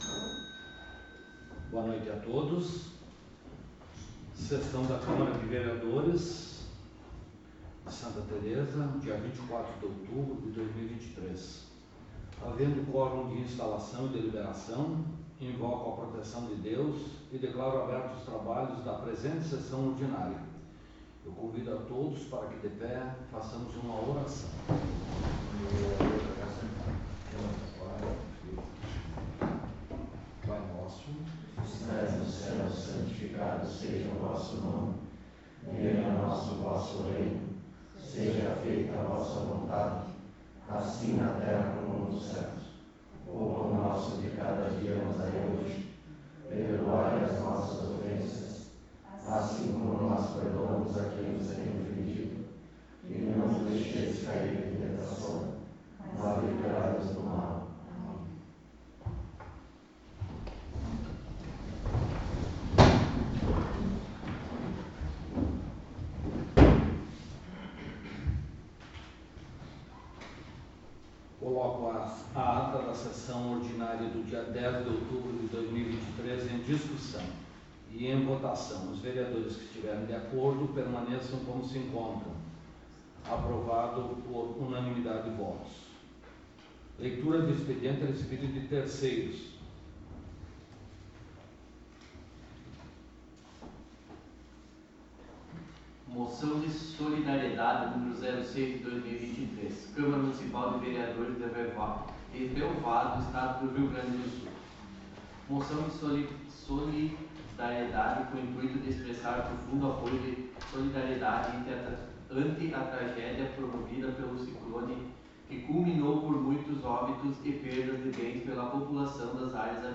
Áudio da Sessão
Local: Câmara Municipal de Vereadores de Santa Tereza